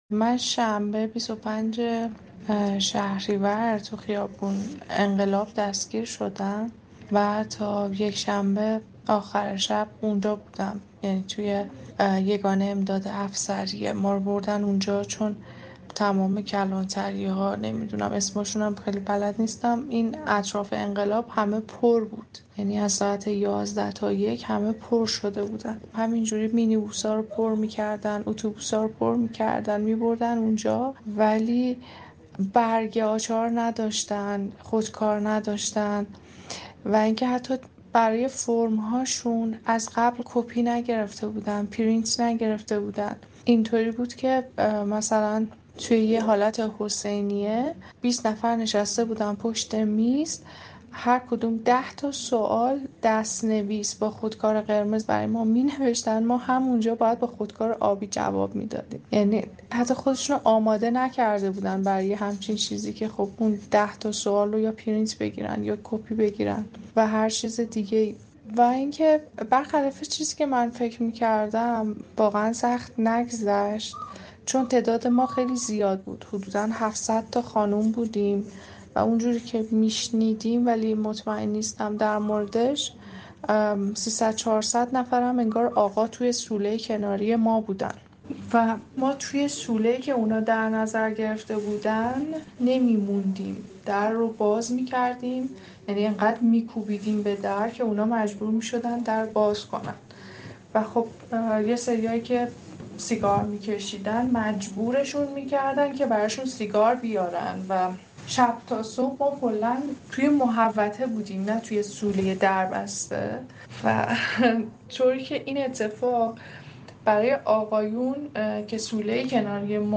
اظهارات این شاهد عینی را با این توضیح بشنوید که صدای او به دلیل رعایت مسائل امنیتی تغییر کرده است.